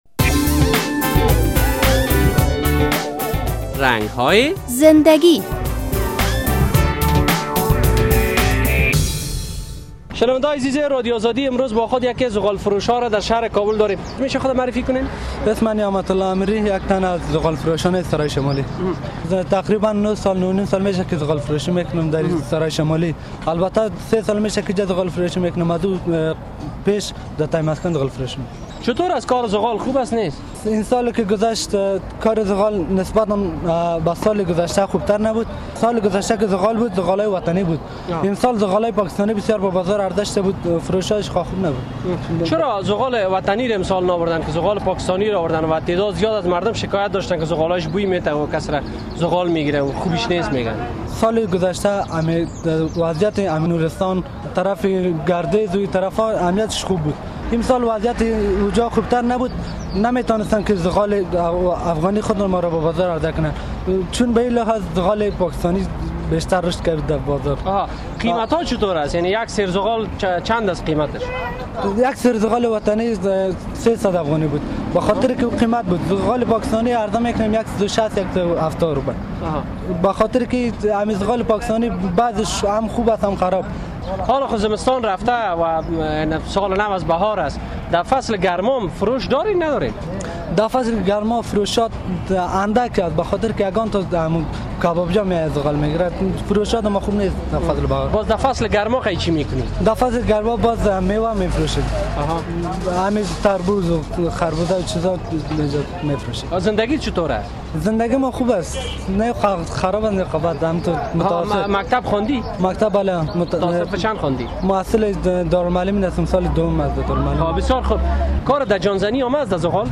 در این برنامهء رنگ های زندگی با یک تن از ذغال فروشان شهر کابل صحبت شده و از وی در مورد فروش ذغال در کابل صحبت شده است. این مصاحبه را با کلیک روی لینک زیر بشنوید...